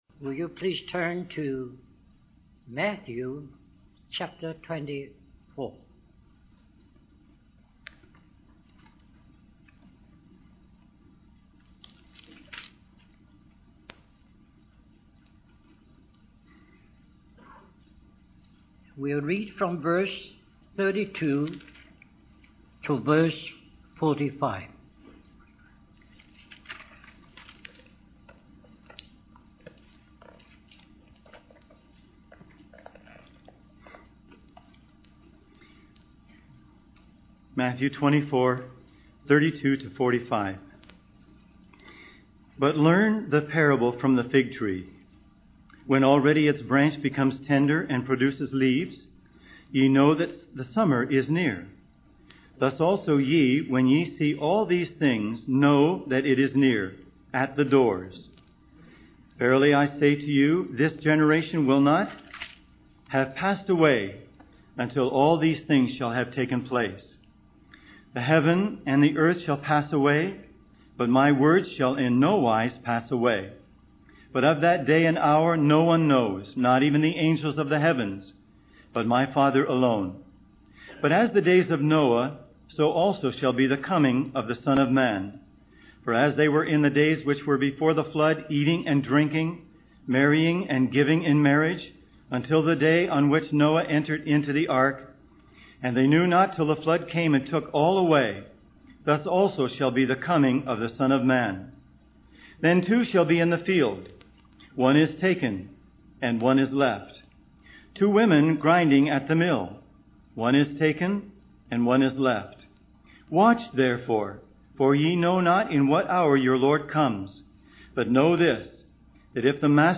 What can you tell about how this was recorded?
Harvey Cedars Conference